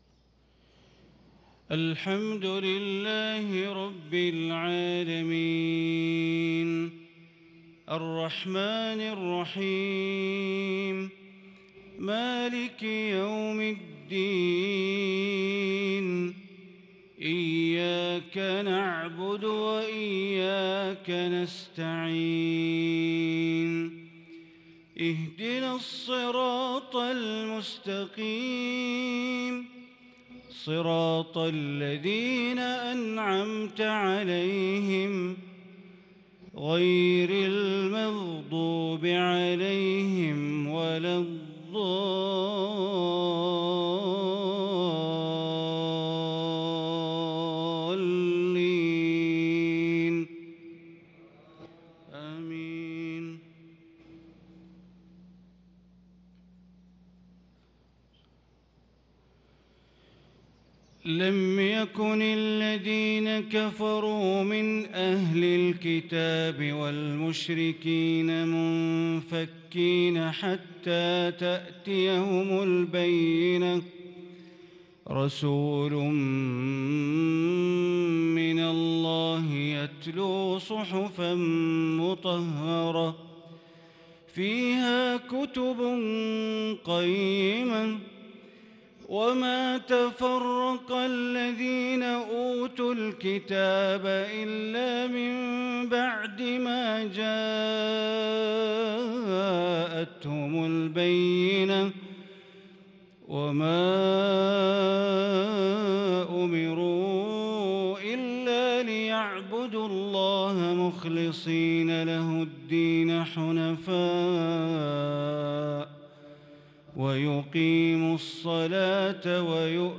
صلاة المغرب للقارئ بندر بليلة 29 ربيع الأول 1443 هـ